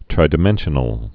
(trīdĭ-mĕnshə-nəl, -dī-)